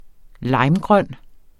Udtale [ ˈlɑjm- ]